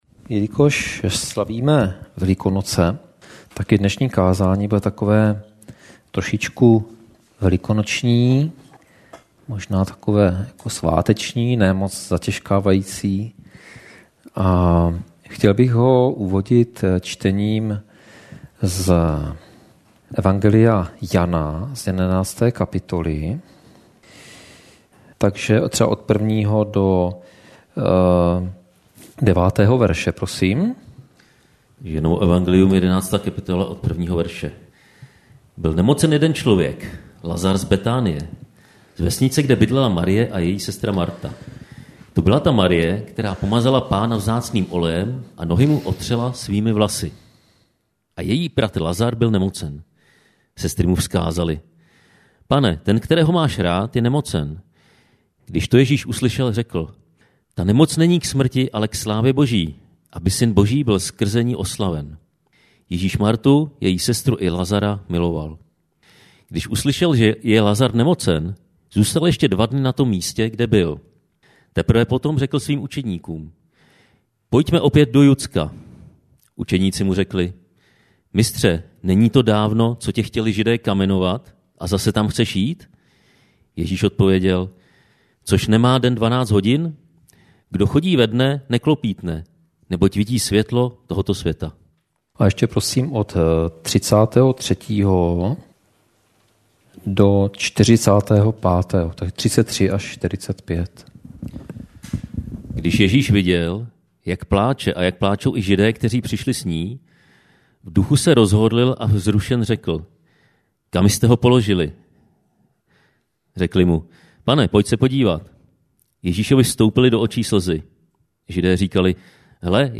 Velikonoční kázání
Kategorie: Nedělní bohoslužby